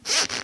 令人毛骨悚然的鞋声" sabata1
描述：惊悚的鞋声
Tag: 爬行